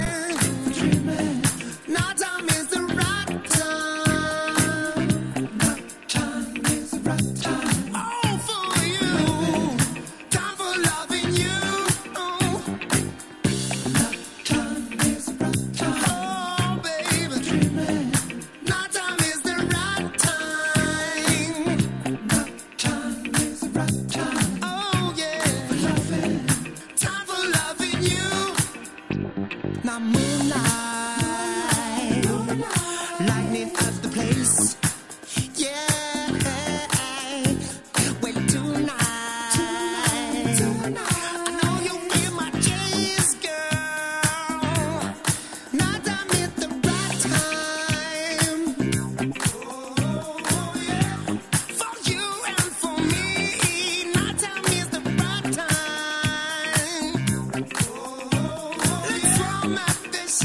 Head straight to the b-side, killer boogie!